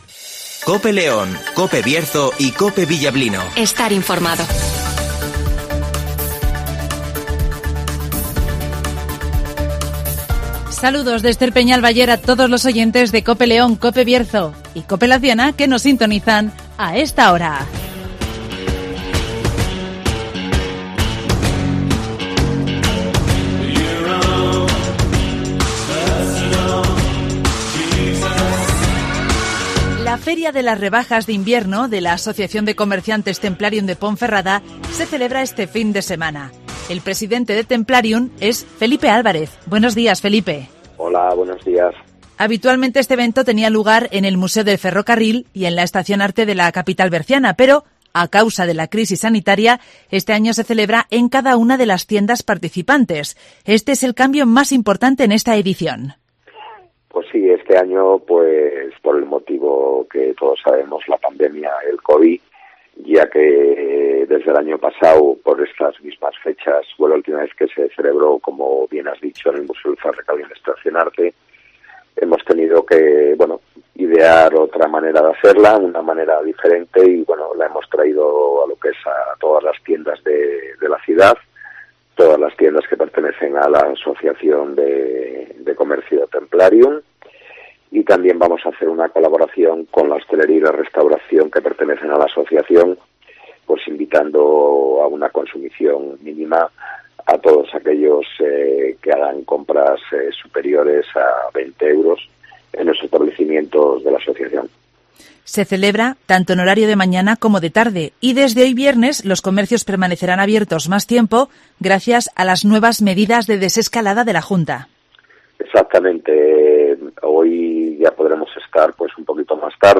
En marcha en Ponferrada, la Feria de la Rebajas de Invierno de Templarium (Entrevista